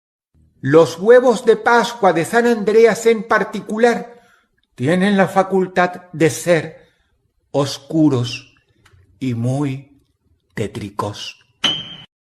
LBcnwhSswMv_los-huevos-de-pascua-de-san-andreas-Dross-efecto-de-sonido--sound-effect.mp3